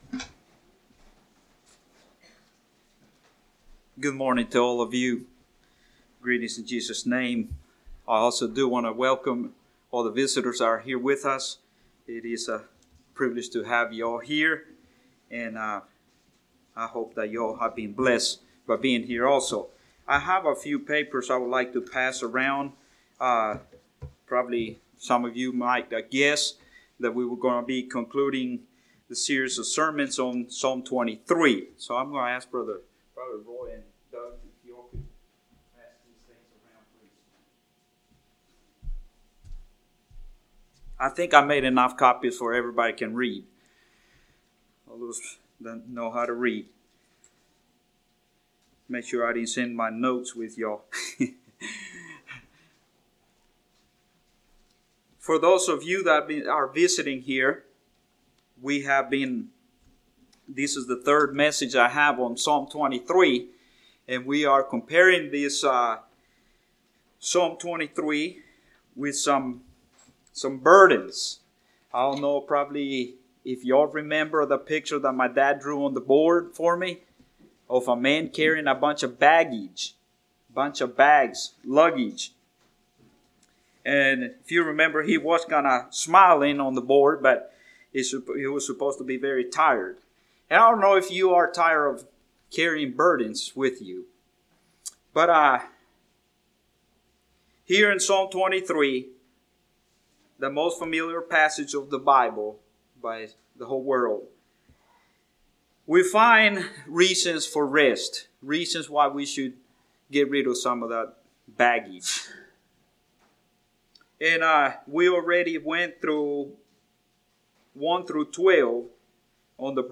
2017 Sermon ID